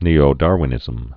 (nēō-därwə-nĭzəm)